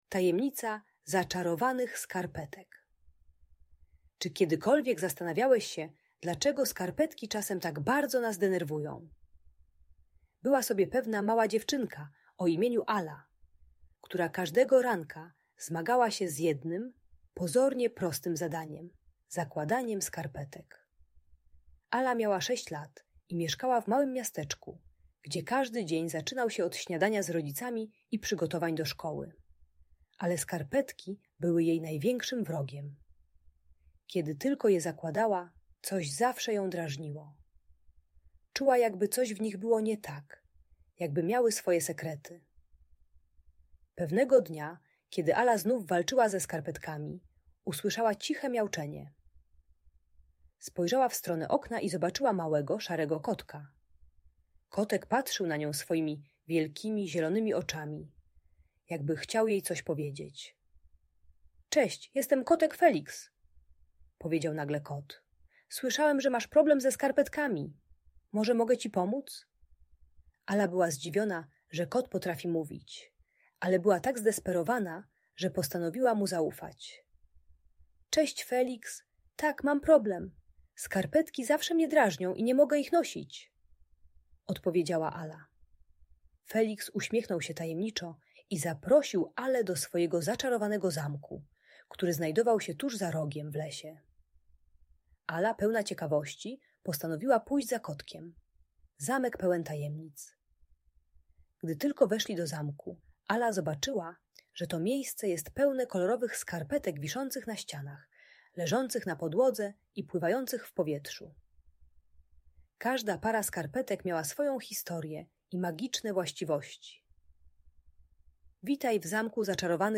Tajemnicza historia zaczarowanych skarpetek - Audiobajka